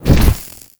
fireball_impact_burn_01.wav